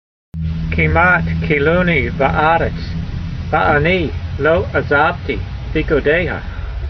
Sound (Psalm 119:87) Transliteration: keem at kee' loo nee va' a reyts , va 'a' nee lo - a' zav tee feeku' dey ha Vocabulary Guide: They almost destroyed me upon the earth , but I did not forsake your precepts . Translation: They almost destroyed me upon the earth, but I did not forsake your precepts.